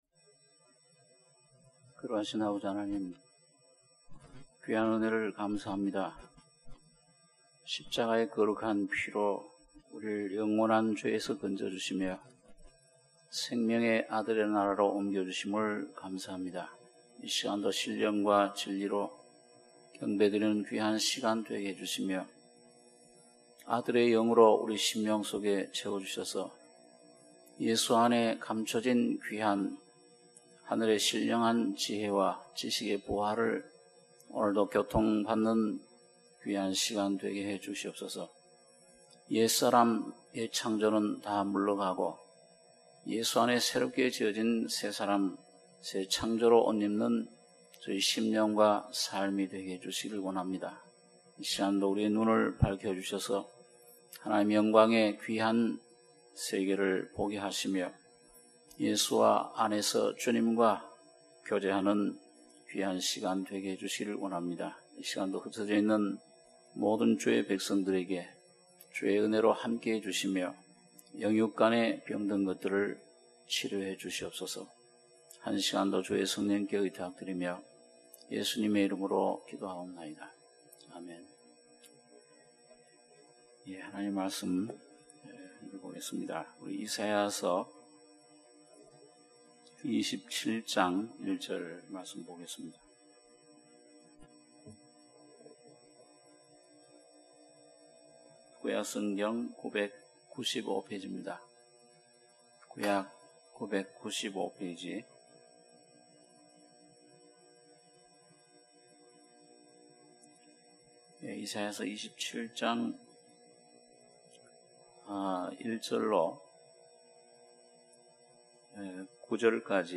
수요예배 - 이사야 27장 1절-9절